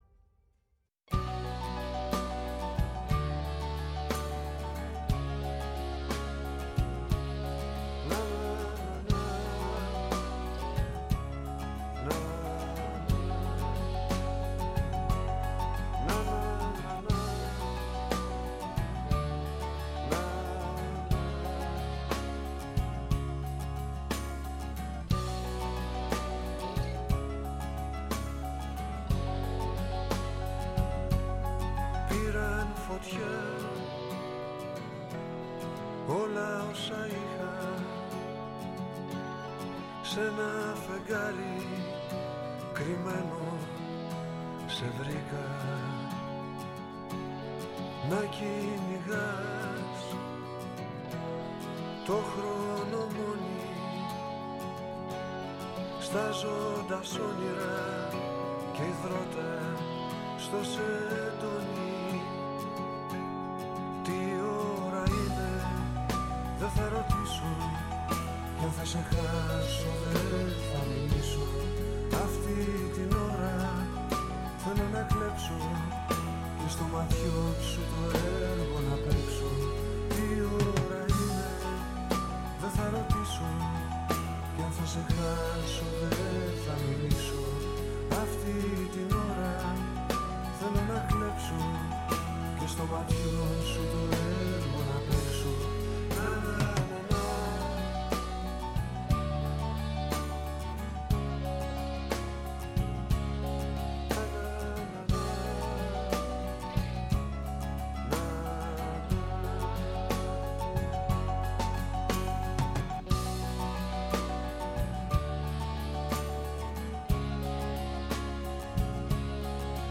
Στην εκπομπή φιλοξενήθηκαν τηλεφωνικά